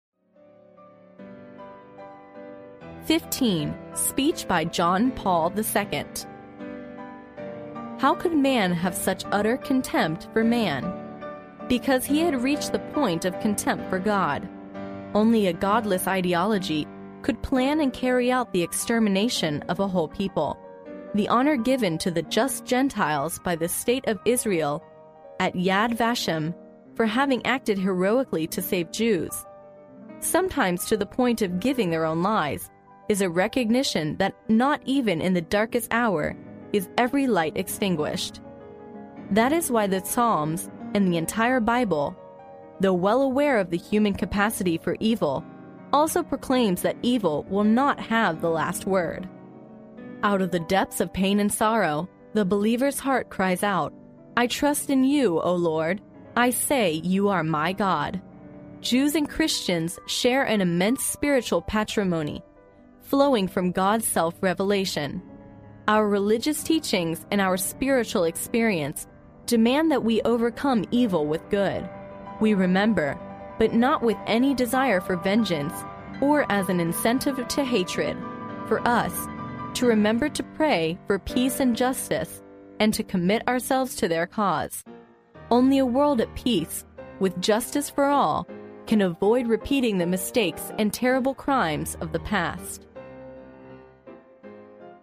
历史英雄名人演讲 第84期:教皇保罗二世演讲 听力文件下载—在线英语听力室